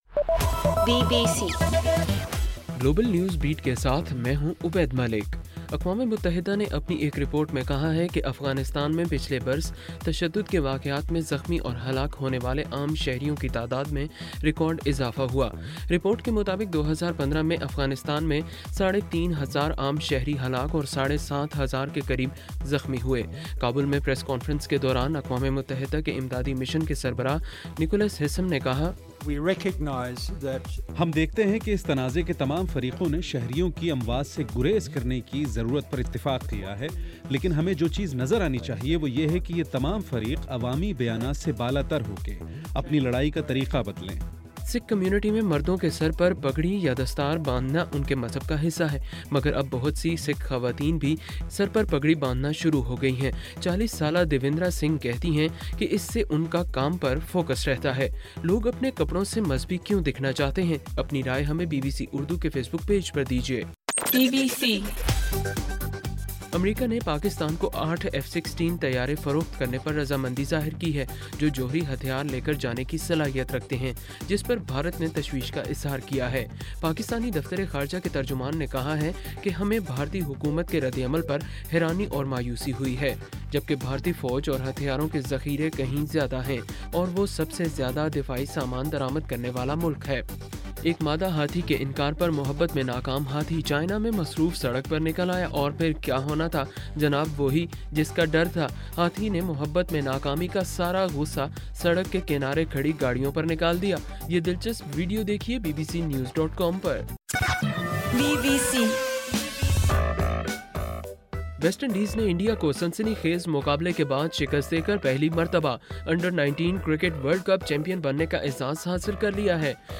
فروری 14: رات 9 بجے کا گلوبل نیوز بیٹ بُلیٹن